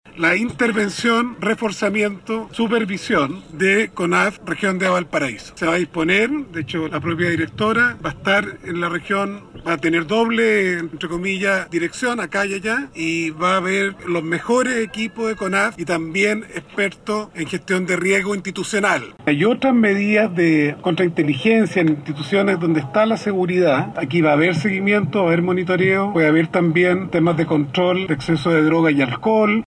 Algunas de estas normas tienen que ver con el monitoreo, seguimiento y también controles de narcotest, informó el ministro de agricultura, Esteban Valenzuela.